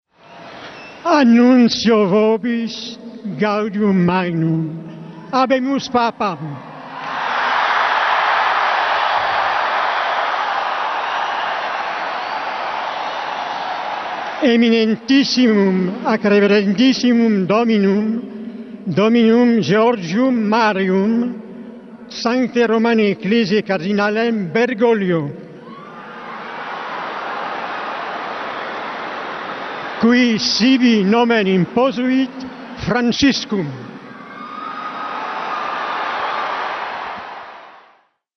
Formula „Habemus papam” a fost rostită de cardinalul francez Jean-Louis Tauran, după care Papa Francisc a apărut la balconul bazilicii „Sfântul Petru” pentru primul mesaj și prima sa binecuvântare „Urbi et Orbi”.
Habemus-Papam-anuntul-oficial.mp3